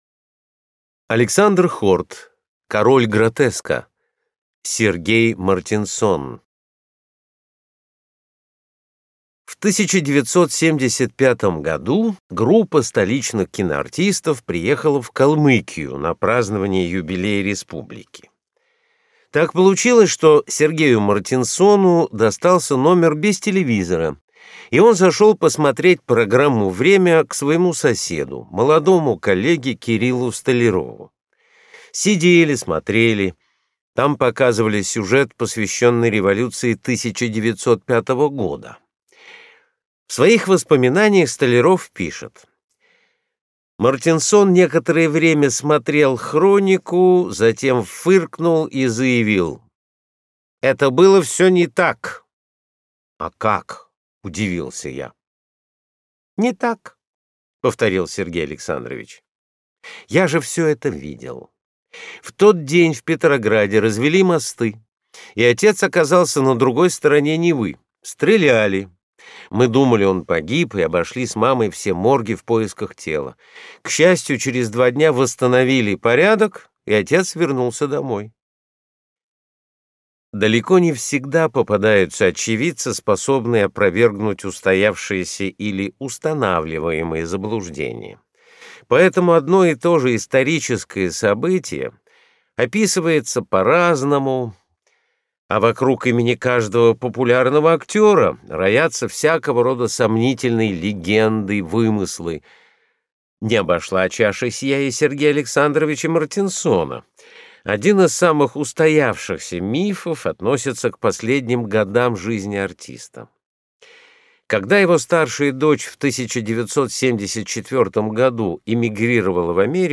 Аудиокнига Король гротеска. Мартинсон | Библиотека аудиокниг